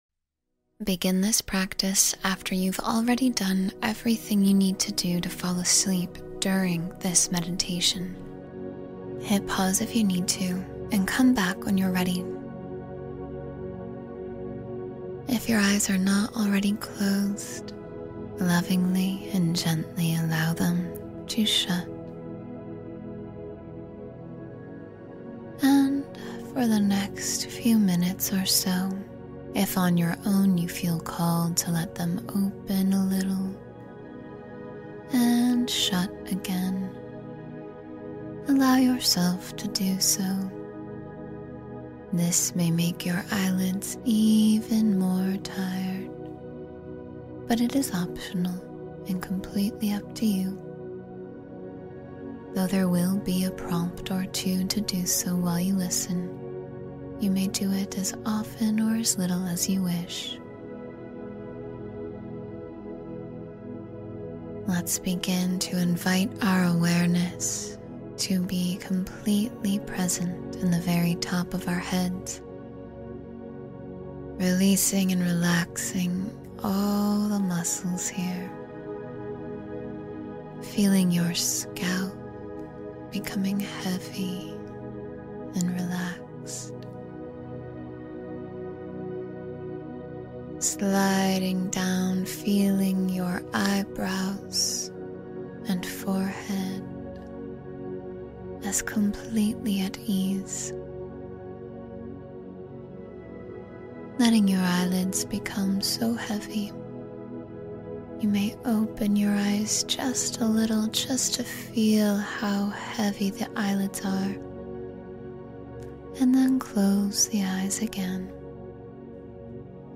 Sleep Talk-Down for Full Body Calm — Guided Meditation for Restorative Sleep